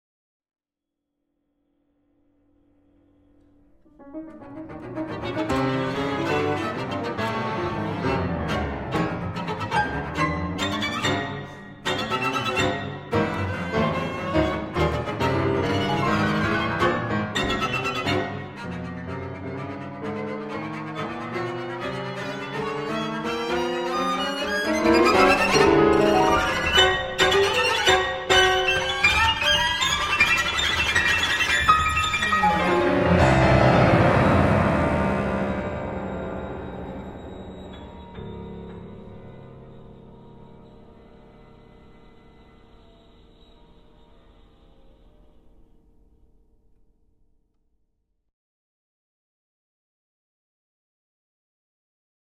Studio recording
violin, violoncello, piano Duration: 12′